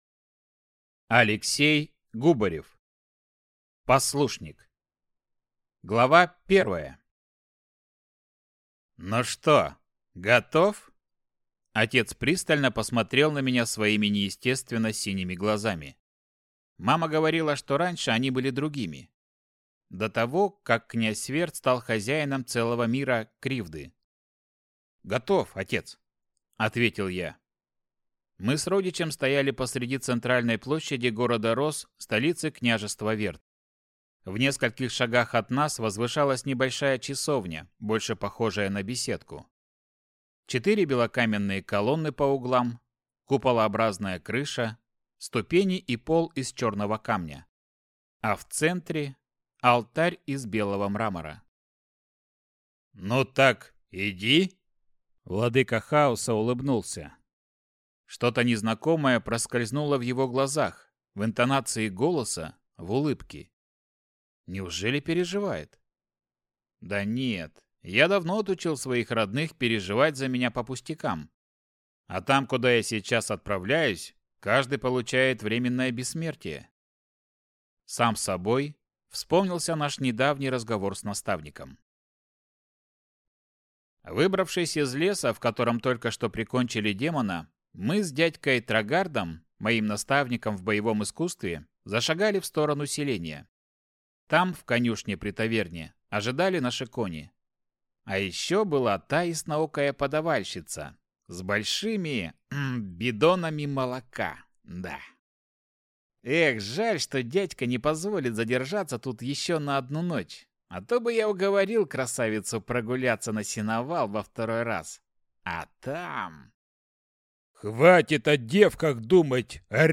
Аудиокнига Послушник | Библиотека аудиокниг
Читает аудиокнигу